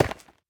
Minecraft Version Minecraft Version 1.21.5 Latest Release | Latest Snapshot 1.21.5 / assets / minecraft / sounds / block / ancient_debris / break1.ogg Compare With Compare With Latest Release | Latest Snapshot
break1.ogg